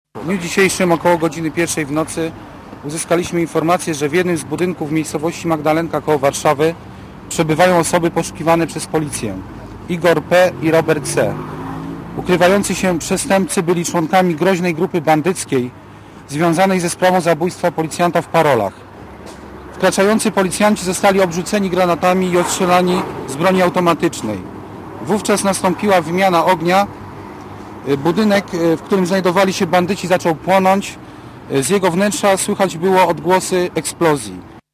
Dla Radia Zet mówi policjant